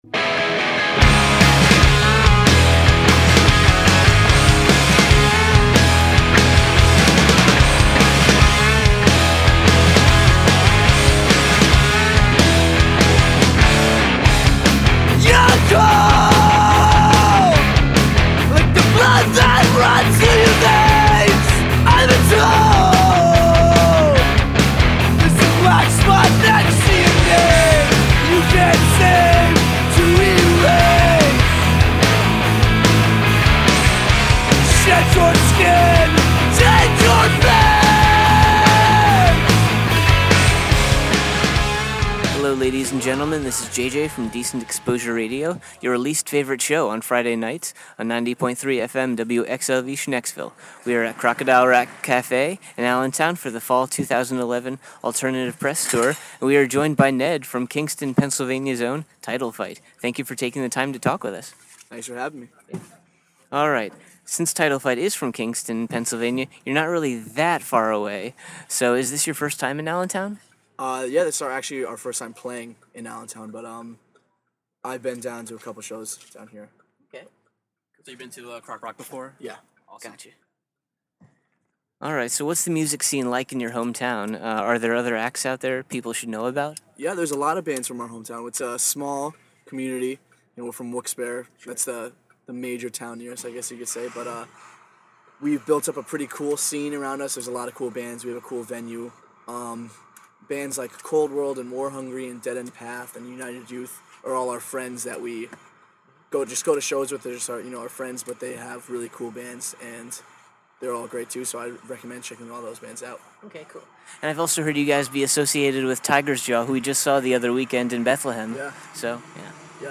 Exclusive: Title Fight Interview
11-interview-title-fight.mp3